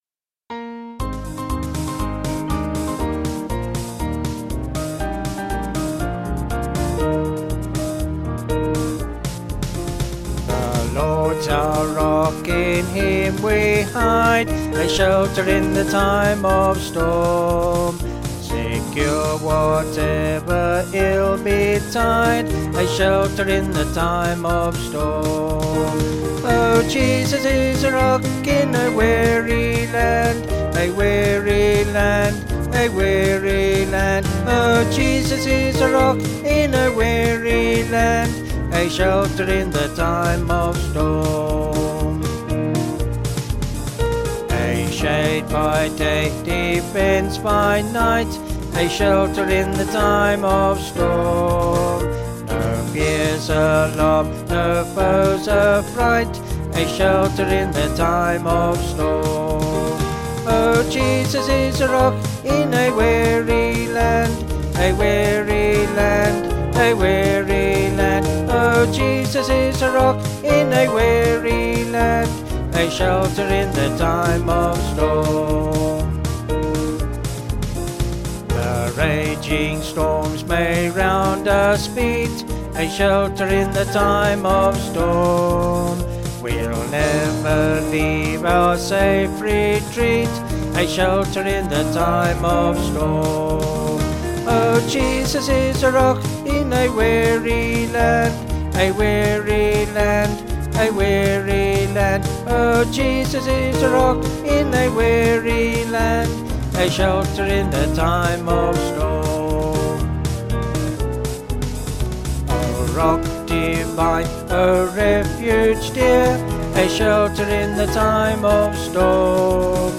Vocals and Band   264.8kb Sung Lyrics